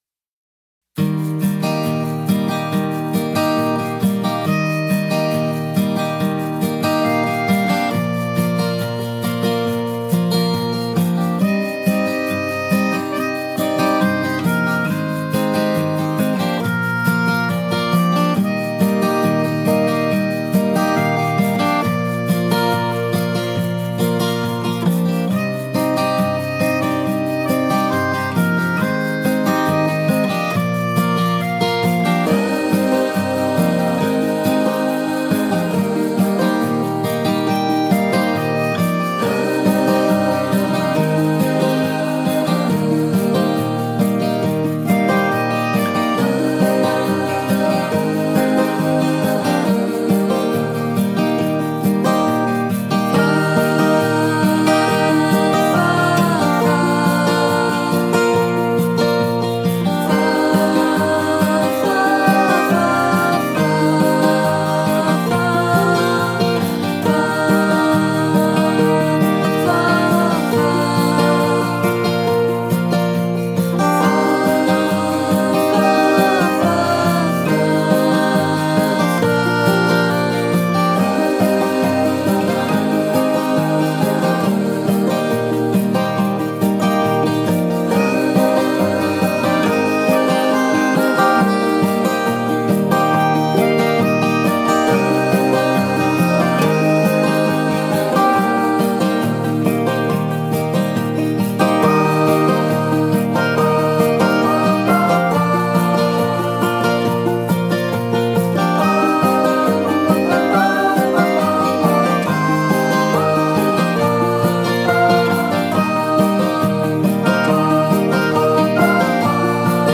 MUSICA-ALLEGRA-16-BELLA.mp3